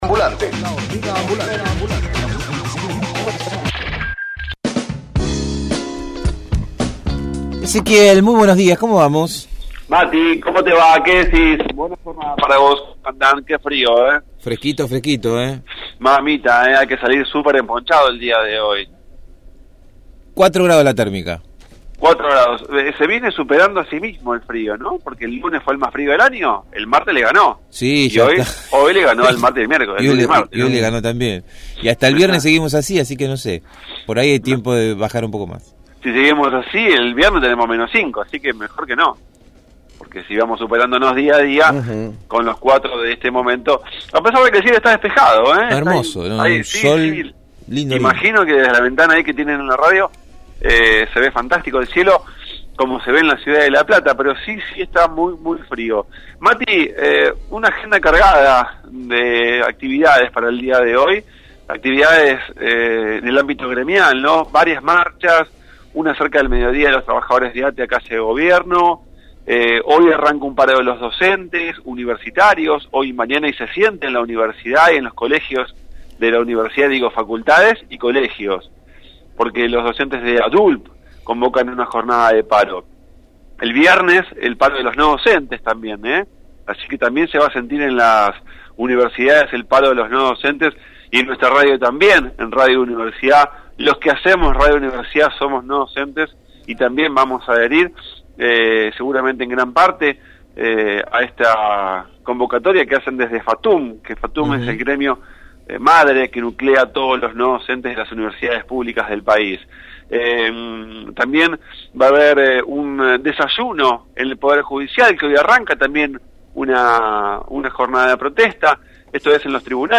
MÓVIL/ Incendio en la Escuela N°3 de Berisso